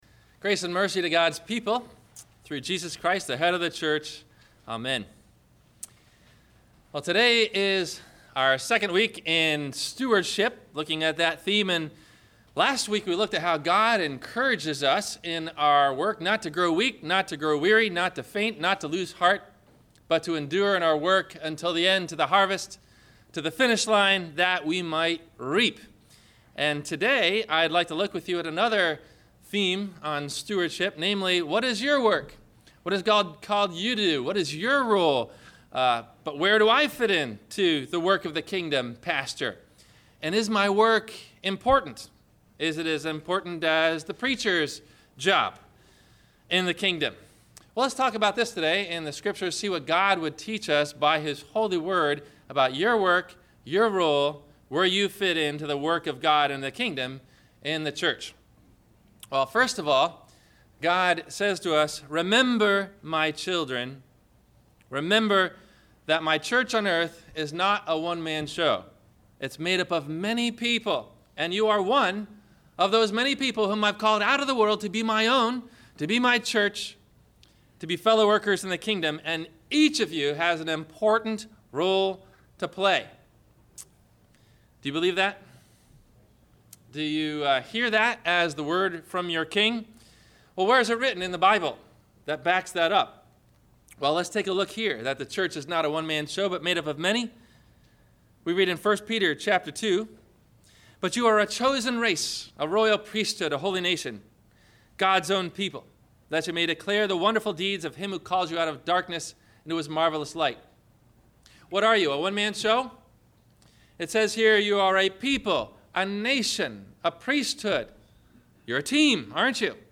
What Part of the Body of Christ Are You? – Sermon – November 23 2014